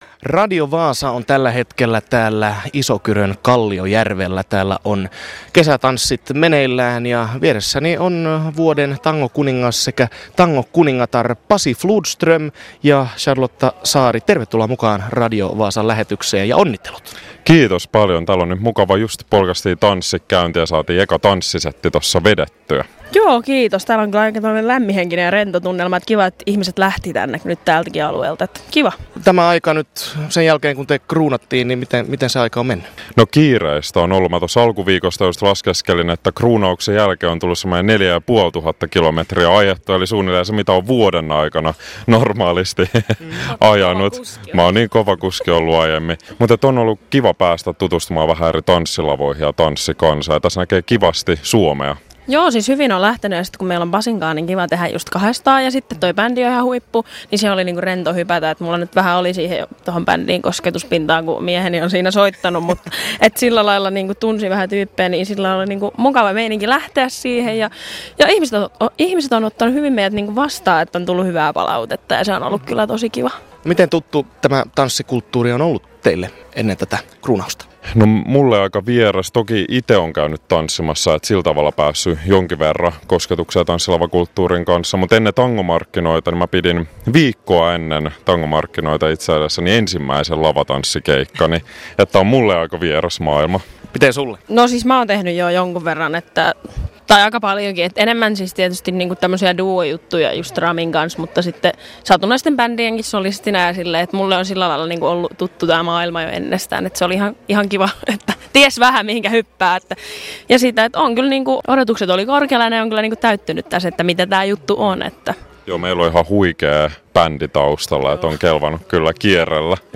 Tämän vuoden tangokuninkaalliset esiintyi Kalliojärvellä 26.7